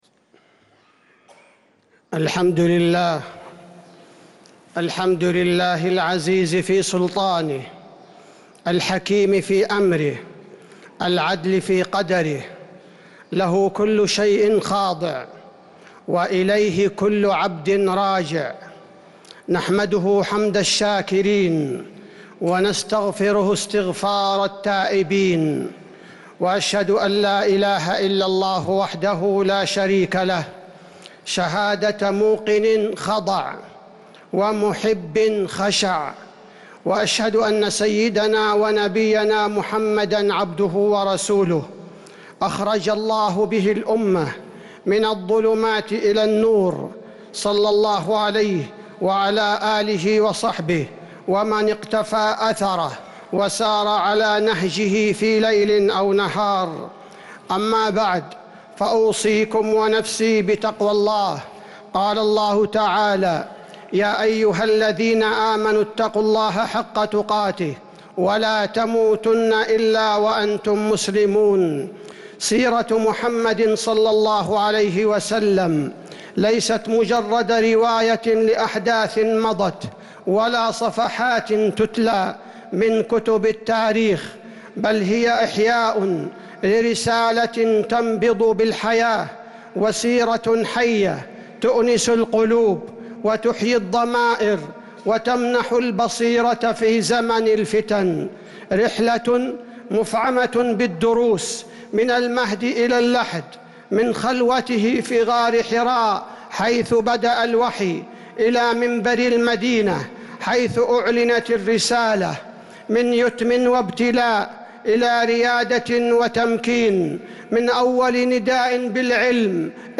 خطبة الجمعة 11 ذو القعدة 1446هـ | Khutbah Jumu’ah 9-5-2025 > خطب الحرم النبوي عام 1446 🕌 > خطب الحرم النبوي 🕌 > المزيد - تلاوات الحرمين